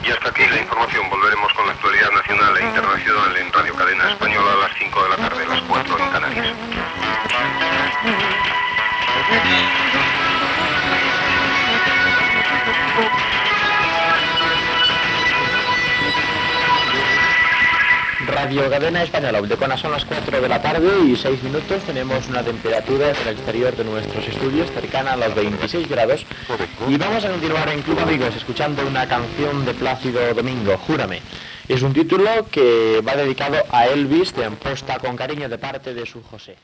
Final notícies RCE, identificació i disc dedicat.
FM